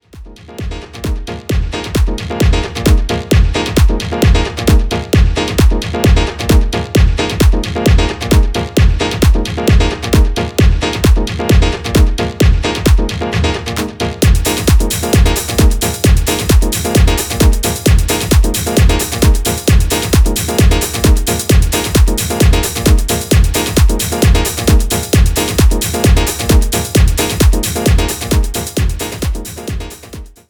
concise, effective club tracks, rooted in
seamlessly transitioning into a hypnotic
groove that’s ideal for long blends and transitions.